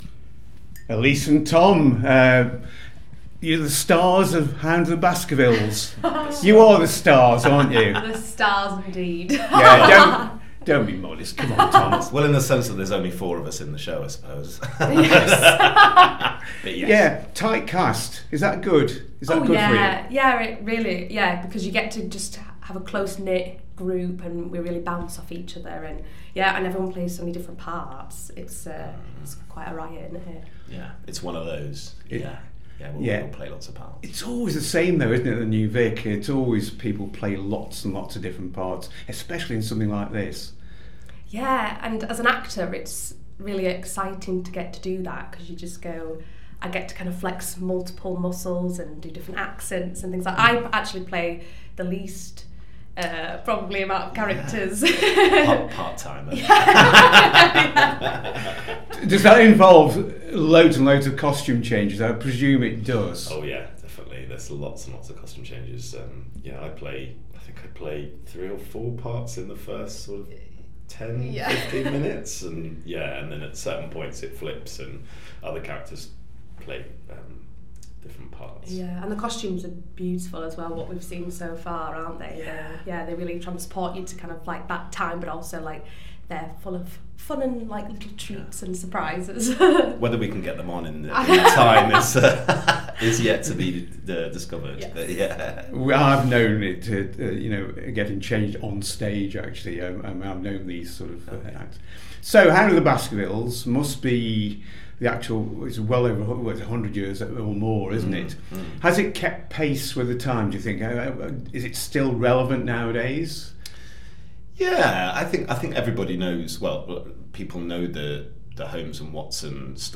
In Conversation with Stars of The Hound of the Baskervilles
Meanwhile have a listen to two of the four actors from the show.